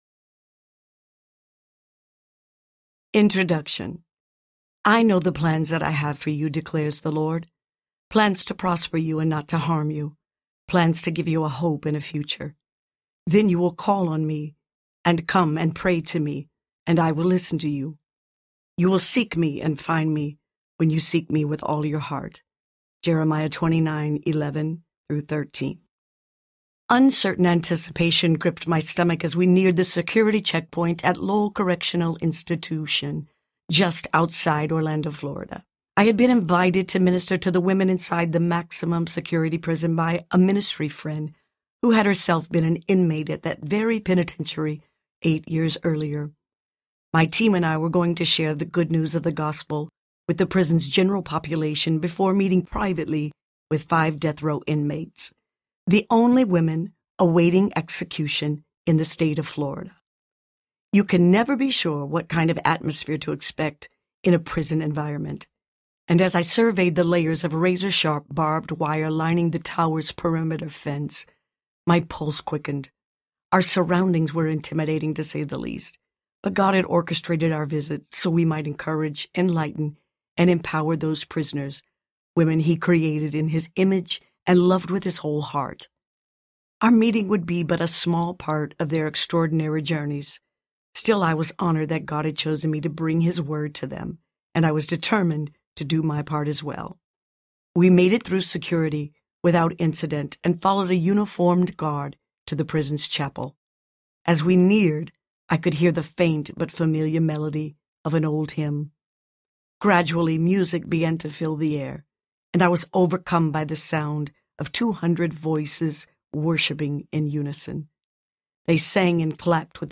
Don’t Miss the Moment Audiobook
5.8 Hrs. – Unabridged